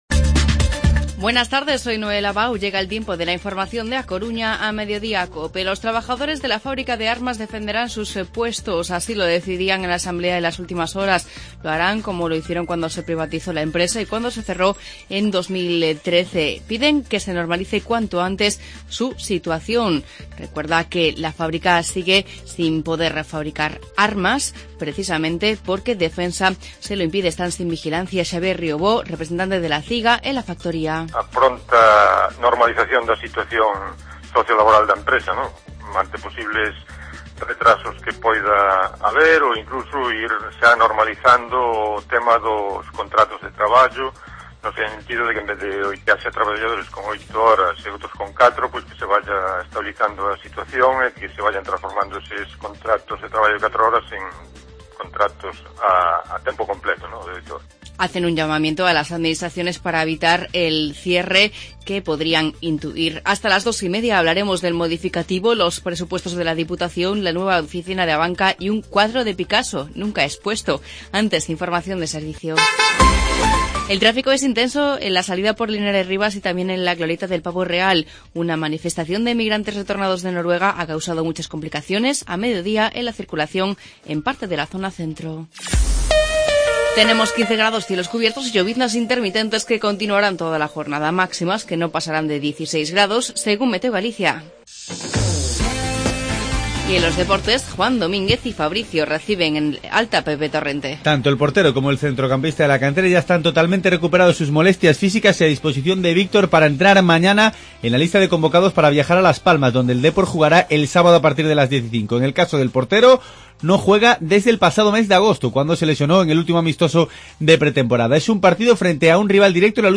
Informativo Mediodía COPE en Coruña jueves 26 de noviembre de 2015